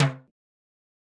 Perc (1).wav